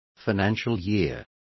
Also find out how ejercicios is pronounced correctly.